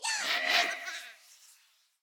Minecraft Version Minecraft Version snapshot Latest Release | Latest Snapshot snapshot / assets / minecraft / sounds / mob / vex / charge2.ogg Compare With Compare With Latest Release | Latest Snapshot
charge2.ogg